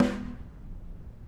Snare2-HitSN_v1_rr1_Sum.wav